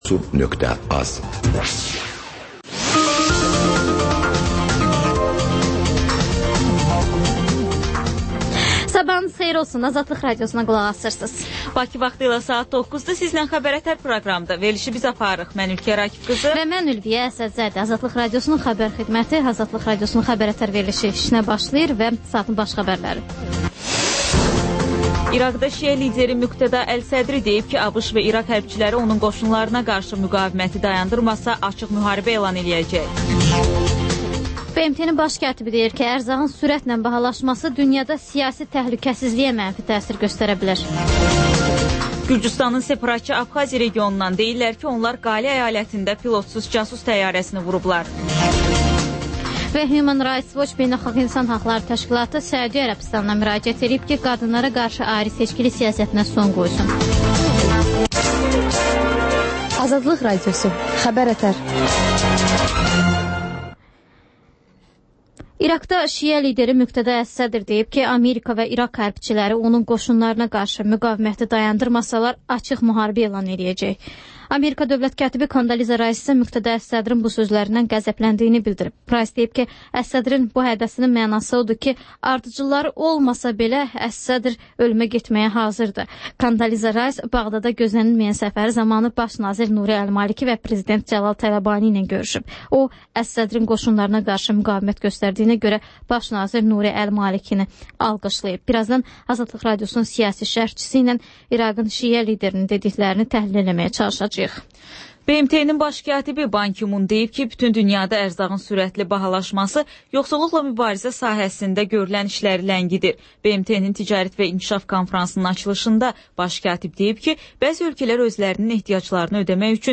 Xəbərlər, müsahibələr.